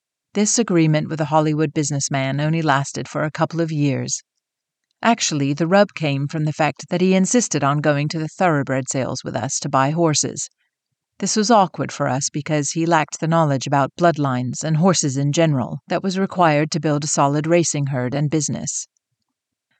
Noise/feedback when listening back
Only when talking, not during pauses/silence. It’s very subtle.